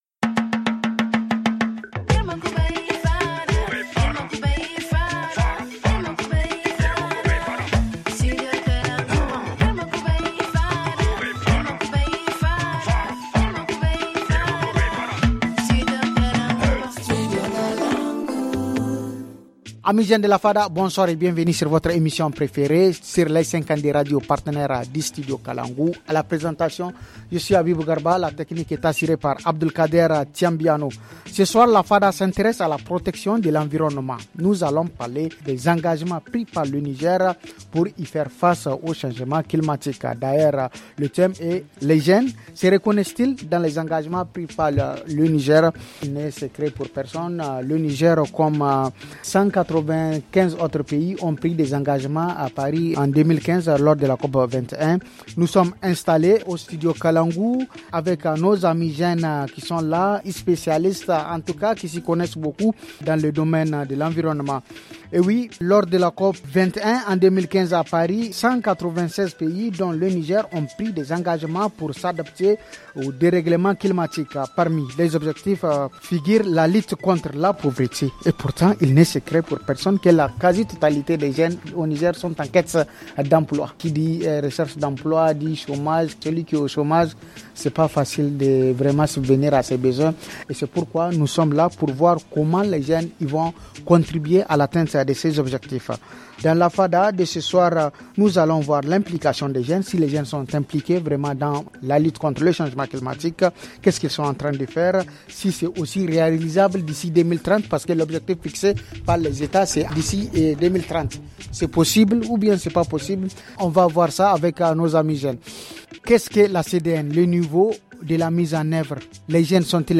Pour discuter de ces questions, nous accueillons ce soir :